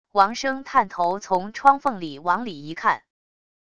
王生探头从窗缝里往里一看wav音频生成系统WAV Audio Player